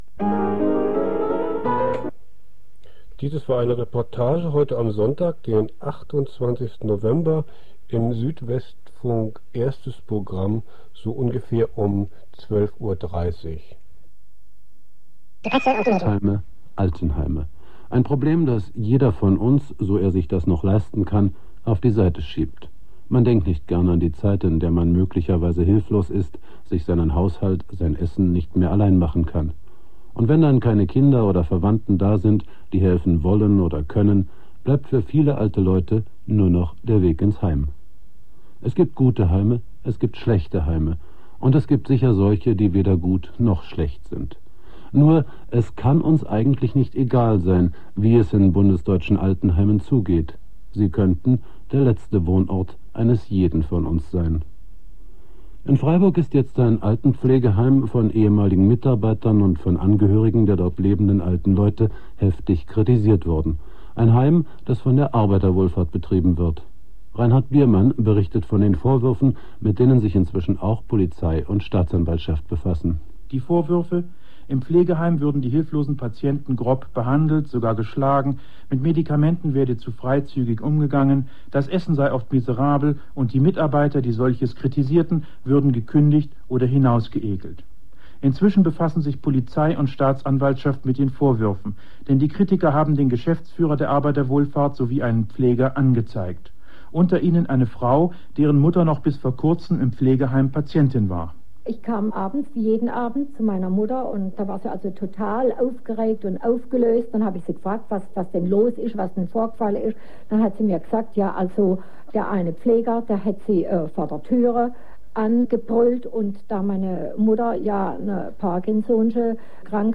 Südwestfunk-Reportage Altenpfleger